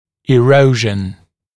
[ɪ’rəuʒ(ə)n][и’роуж(э)н]эрозия, разъедание; разрушение